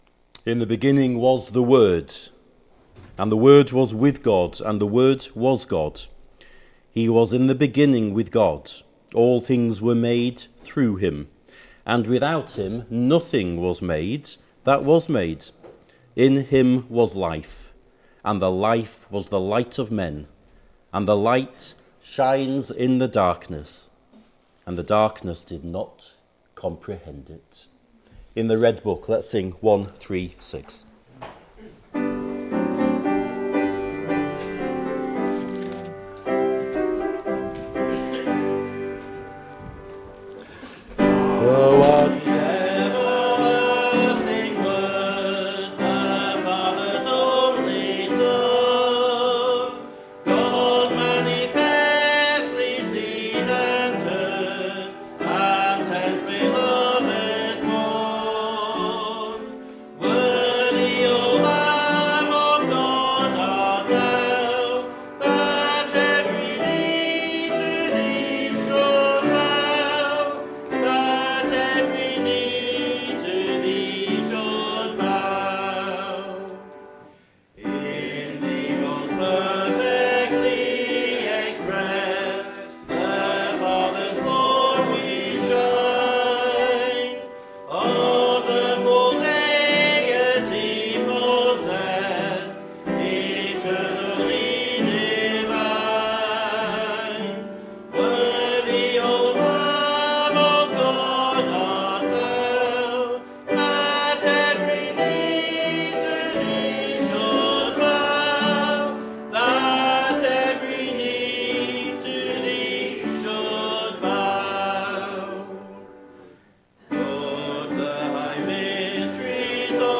Below is audio of the full service.
2025-12-14 Morning Worship If you listen to the whole service on here (as opposed to just the sermon), would you let us know?